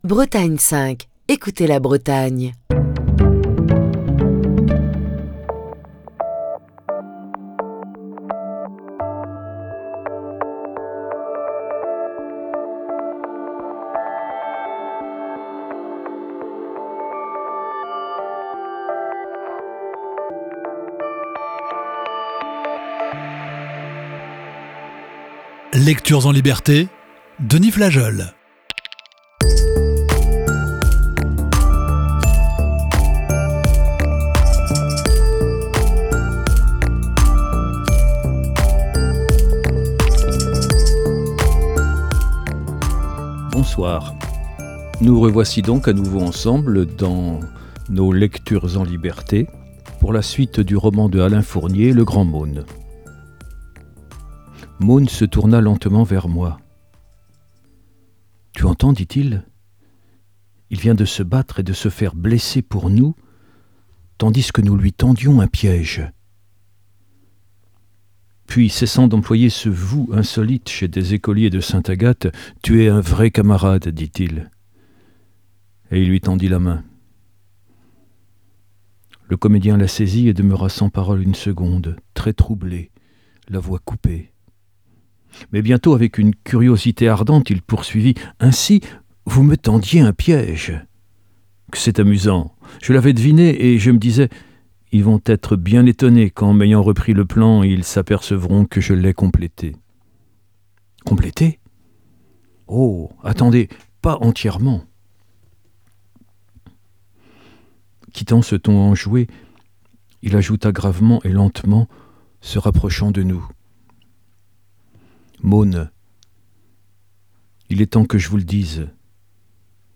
Émission du 13 décembre 2023.